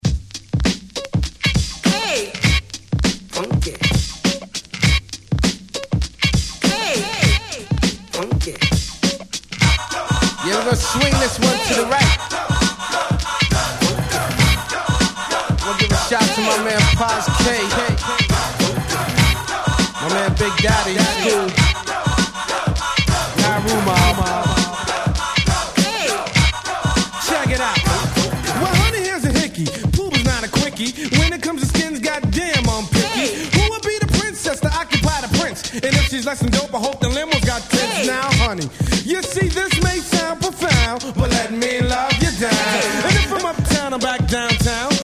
※試聴ファイルは別の盤から録音してございます。